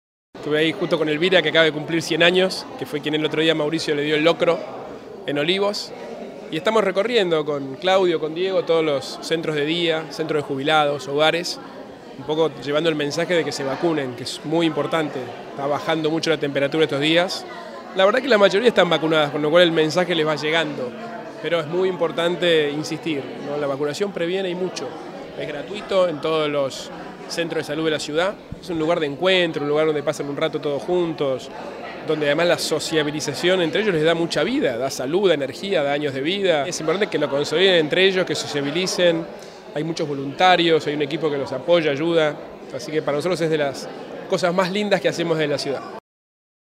“Estamos recorriendo todos los centros de día, centros de jubilados y hogares, llevando el mensaje de que se vacunen, porque es muy importante", resaltó Horacio Rodríguez Larreta en el establecimiento de Palermo (Paraguay 5170).
El jefe de Gobierno de la Ciudad de Buenos Aires, Horacio Rodríguez Larreta, visitó el Centro de Día N° 9, ubicado en el barrio de Palermo, donde compartió una merienda con adultos mayores y dialogó sobre las recomendaciones para evitar la Gripe A y la necesidad de aplicarse la vacuna correspondiente.